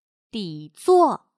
底座/dǐzuò/Base